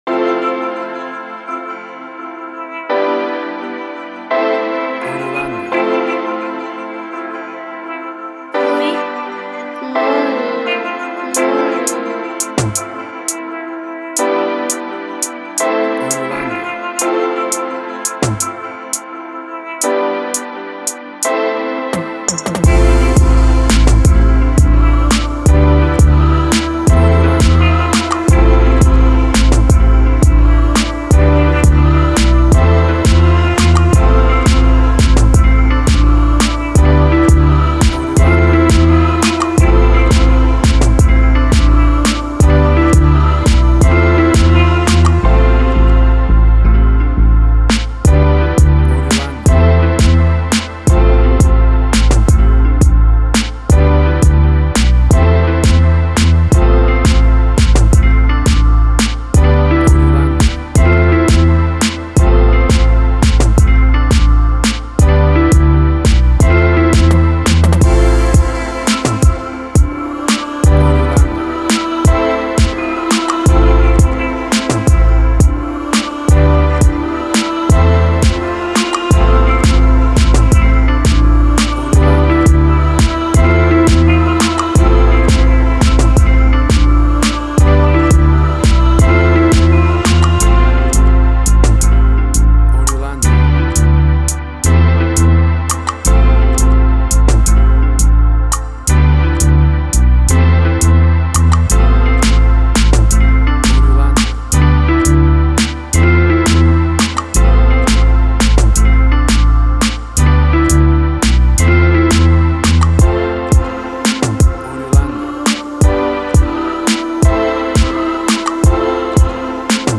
This instrumental has a Dancehall , Reggaeton , Afro Feel.
WAV Sample Rate: 24-Bit stereo, 44.1 kHz
Tempo (BPM): 86